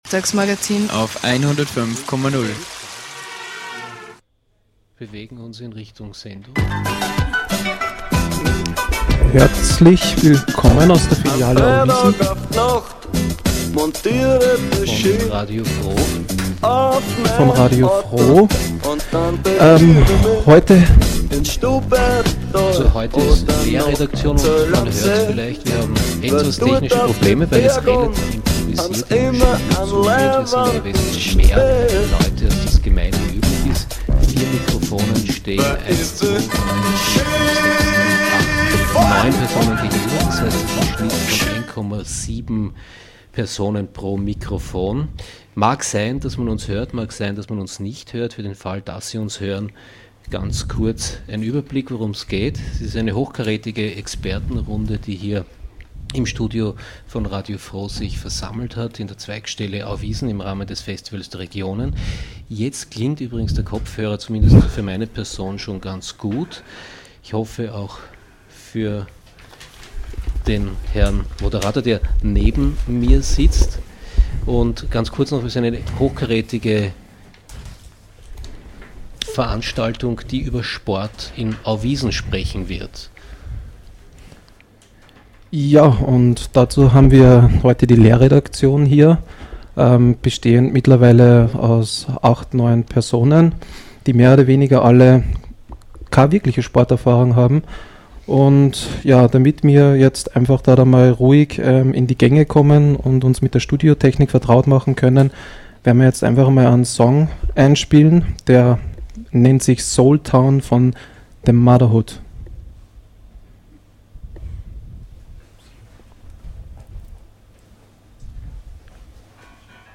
Diese Sendung der LehrredaktionsteilnehmerInnen von Radio FRO beschäftigt sich mit dem Thema Sport in Auwiesen.